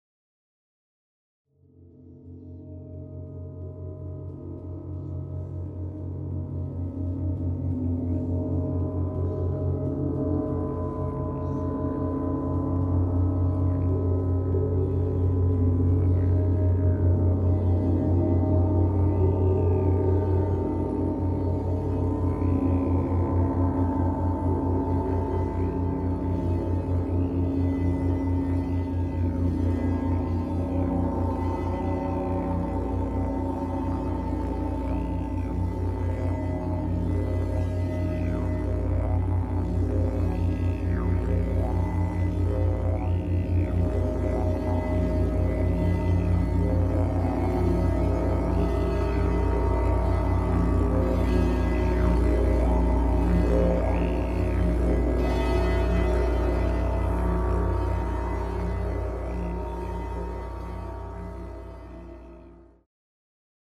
Musik Klangschalen und Planetentöne